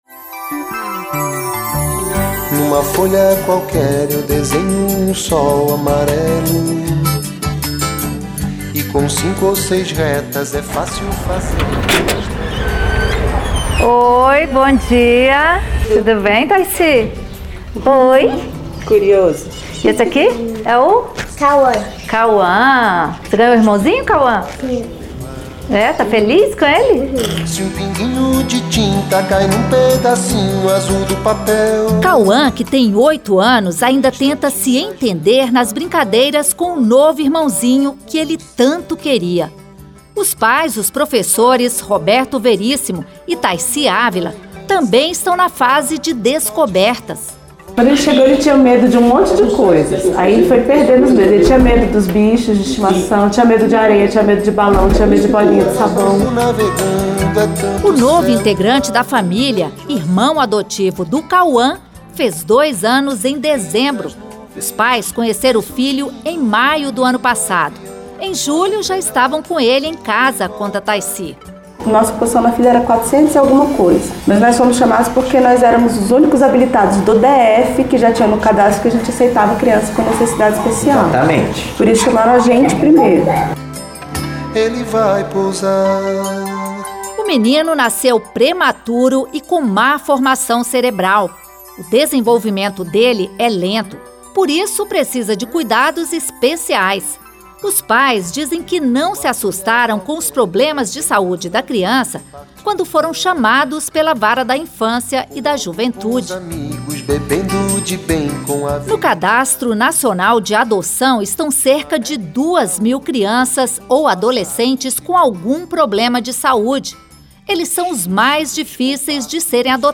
A adoção é o tema da reportagem especial que estreia no dia 14 de maio, na Rádio Senado.